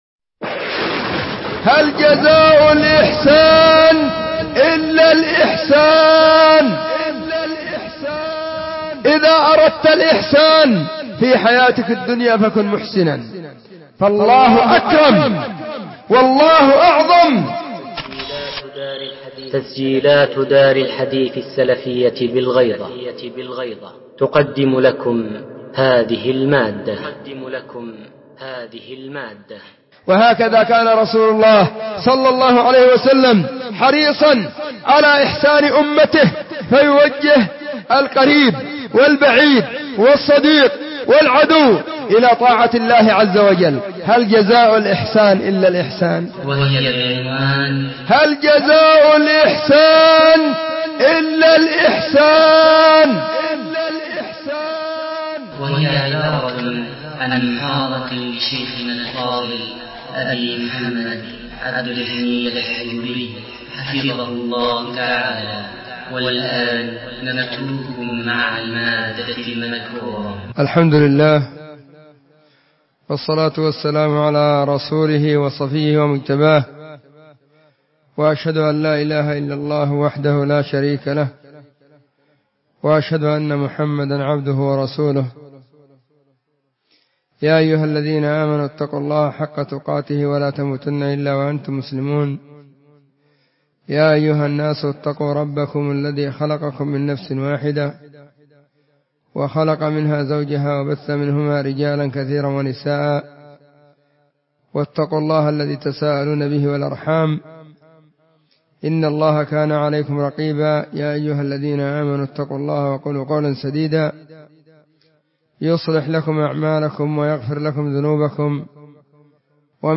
محاضرة بعنوان *((هَلْ جَزَاءُ الْإِحْسَانِ إِلَّا الْإِحْسَانُ))*
📢 مسجد الصحابة – بالغيضة – المهرة، اليمن حرسها الله،